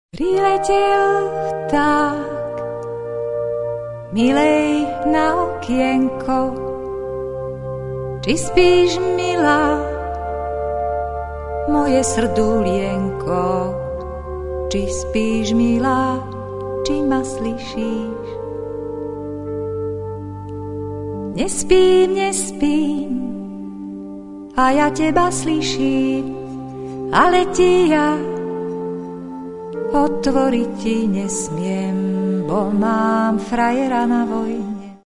legendary Slovak woman singer and songwriter.